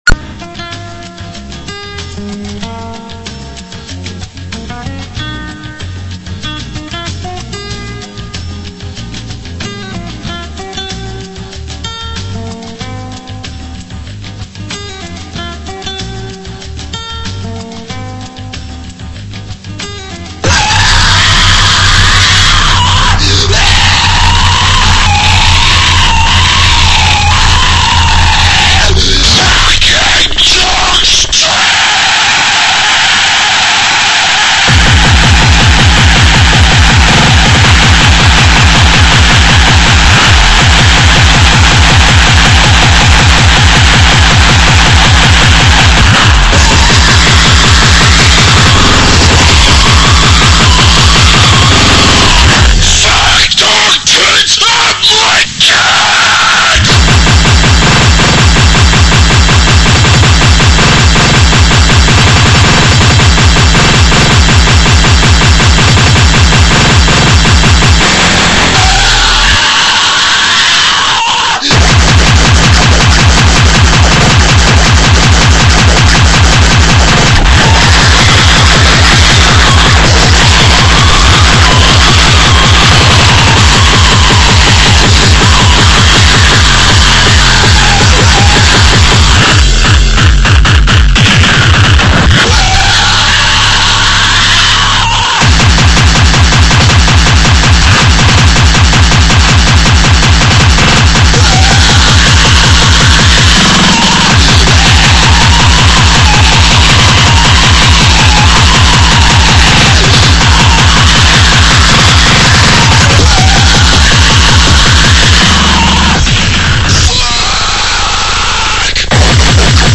la qualité du son est juste, pensez à augmenter un poil le volume.
Ah, heu... ouais, top violent en effet !